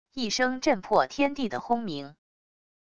一声震破天地的轰鸣wav音频